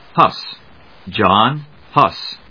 音節Huss 発音記号・読み方/hˈʌs, hˈʊs/発音を聞く